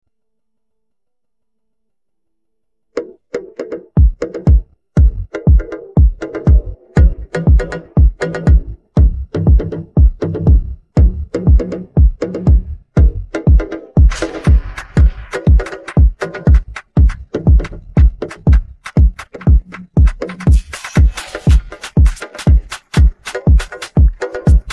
Download Drums sound effect for free.
Drums